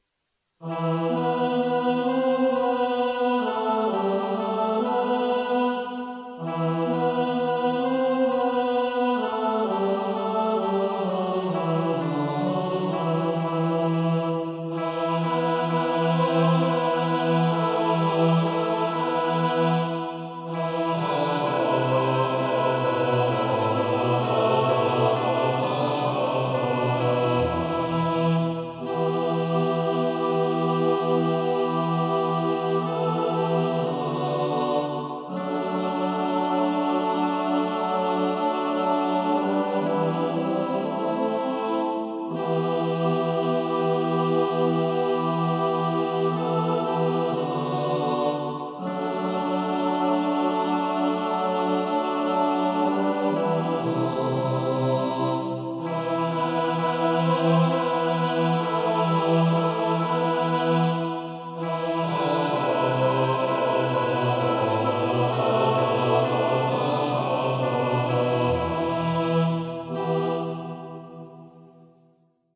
pro smíšený sbor